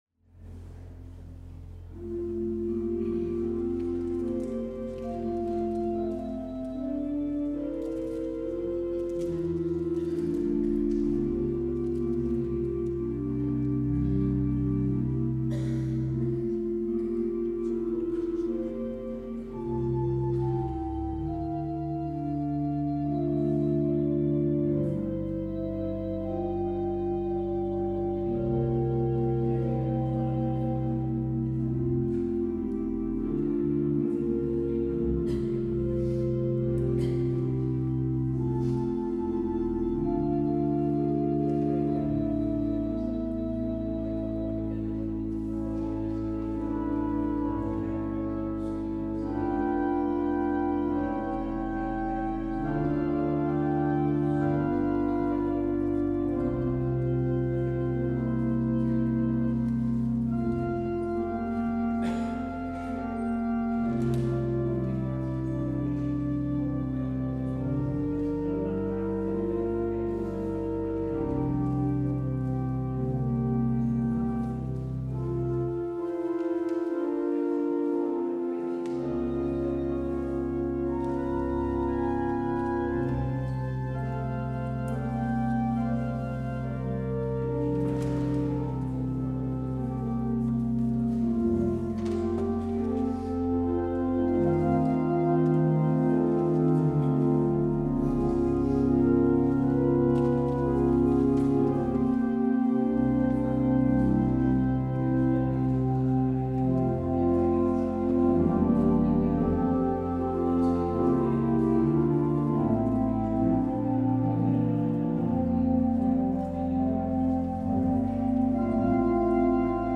Op het orgel speelt